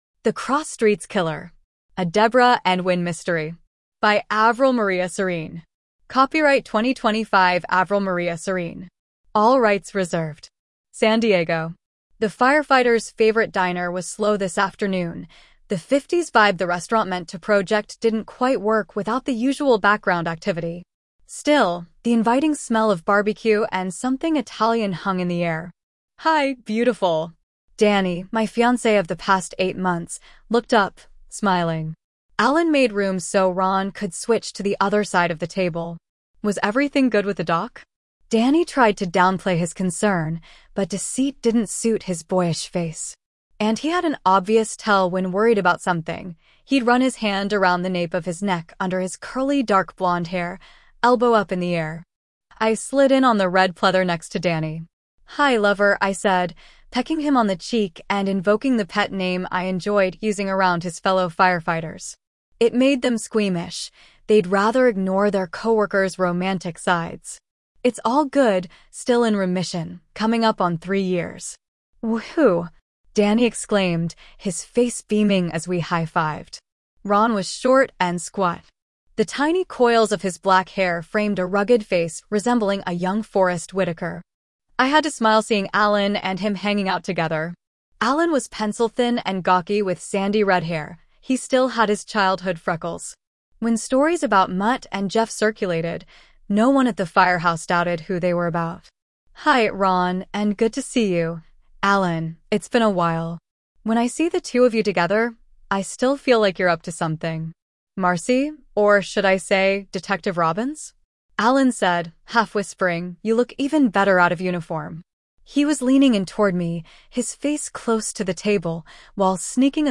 eAudioBook